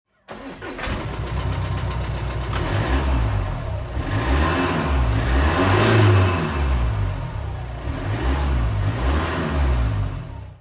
Écoutez le son du moteur !
Range-Rover-Evoque.mp3